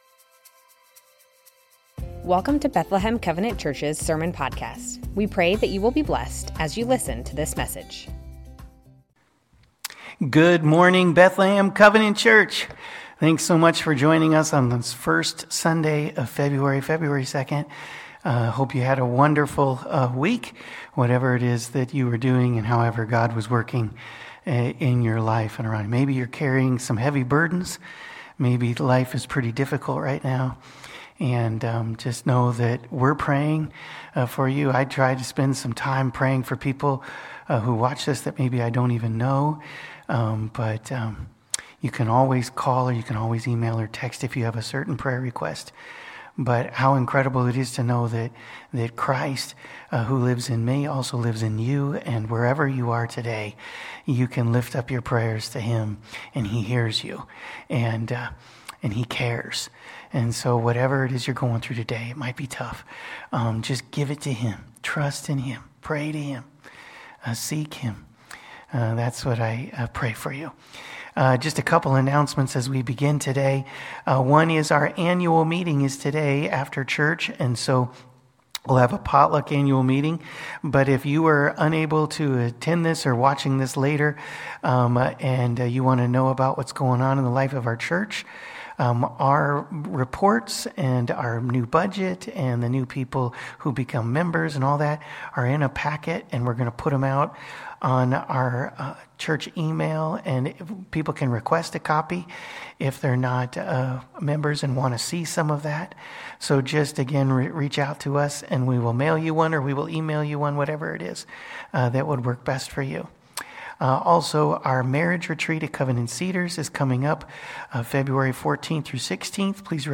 Bethlehem Covenant Church Sermons Matthew 5:1-12 - The good life Feb 02 2025 | 00:34:14 1x 00:00 / 00:34:14 Subscribe Share Spotify RSS Feed Share Link Embed